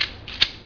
sounds / guns / reload.ogg
reload.ogg